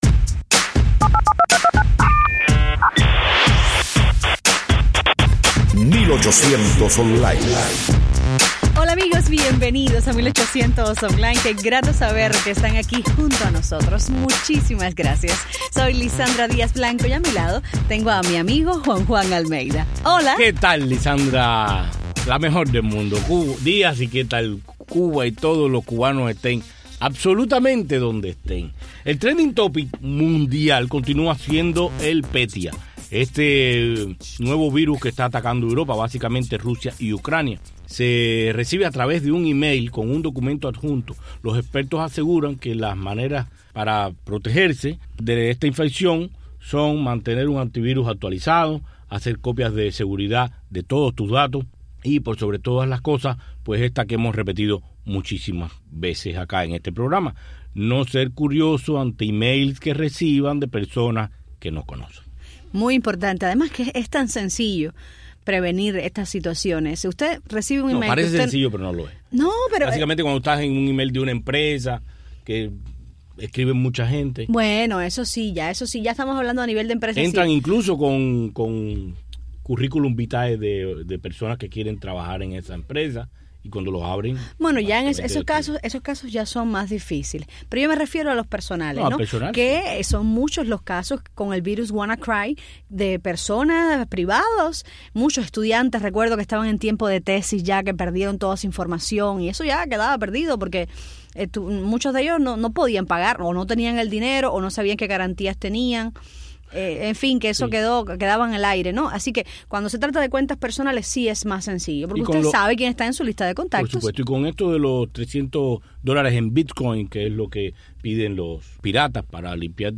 1800 Online con el cantautor mexicano